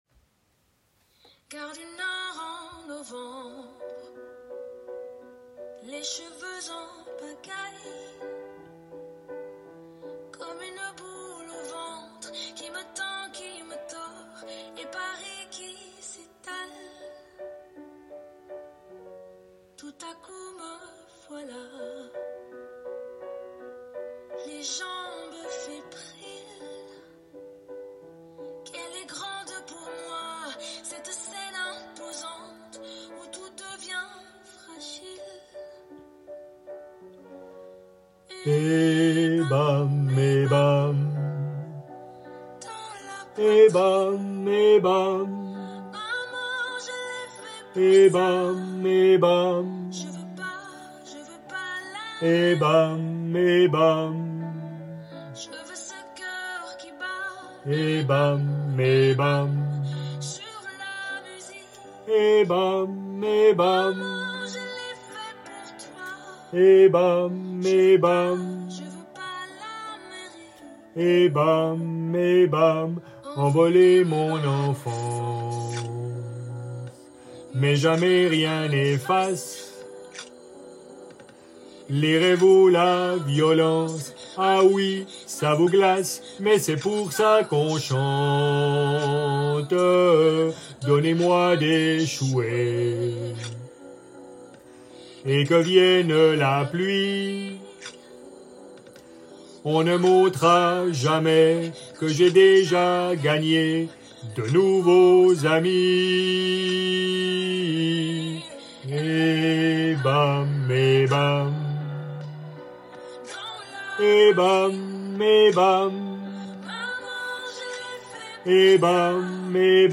Basses | Café-Café | groupe vocal
Basses
Nos MP3 - Basses